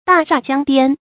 大廈將顛 注音： ㄉㄚˋ ㄕㄚˋ ㄐㄧㄤ ㄉㄧㄢ 讀音讀法： 意思解釋： 喻情勢危急，頻臨崩潰。